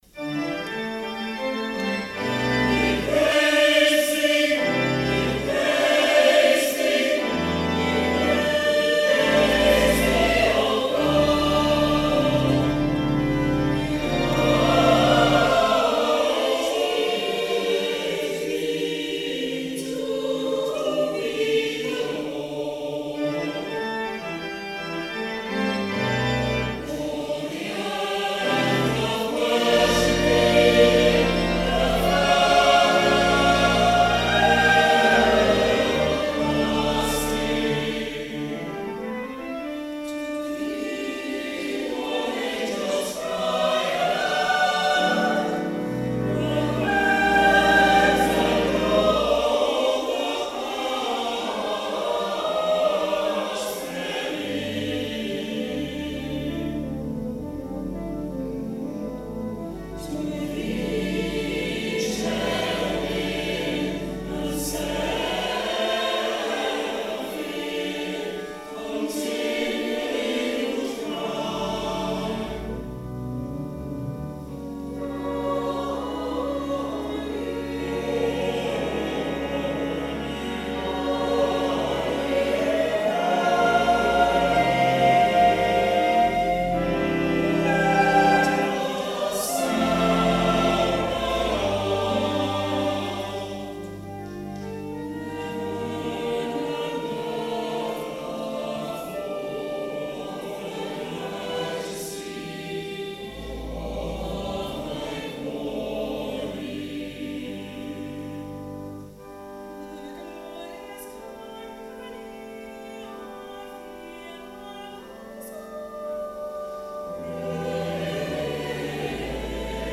On Saturday 1st November 2020, St German's Cathedral, Peel on the Isle of Man, celebrated the 40th anniversary of its consecration with a special Service of Thanksgiving.
The Te Deum was sung during the service by the Cathedral Choir.